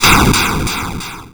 lightning_b.wav